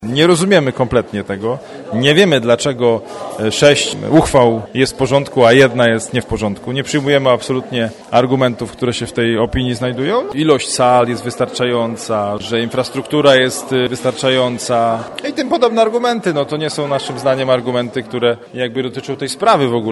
Podkarpacki Kurator Oświaty negatywnie zaopiniował uchwałę Rady Powiatu Dębickiego dotyczącej dodatkowej lokalizacji tej placówki szkolnej w Zespole Szkół Zawodowych nr 1 w Dębicy. Zdziwienia taką decyzją nie kryje Piotr Chęciek starosta dębicki.